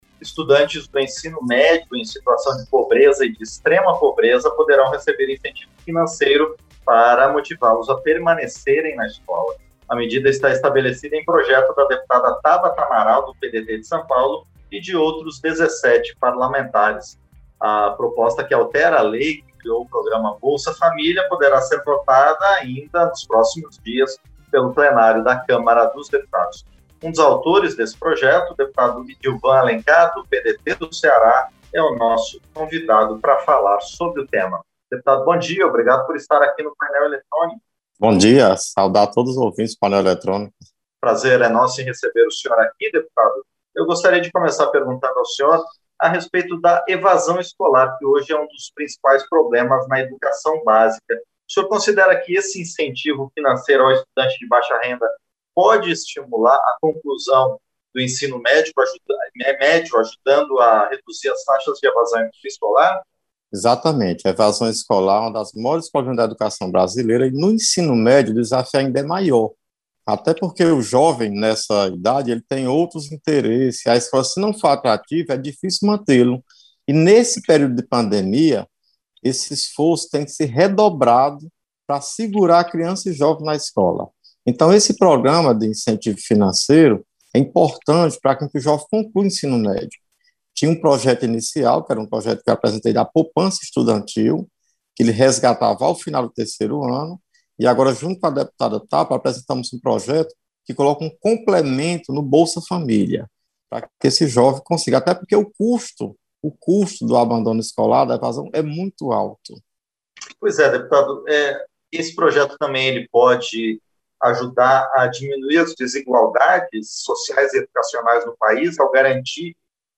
• Entrevista - Dep. Idilvan Alencar (PDT-CE)
Programa ao vivo com reportagens, entrevistas sobre temas relacionados à Câmara dos Deputados, e o que vai ser destaque durante a semana.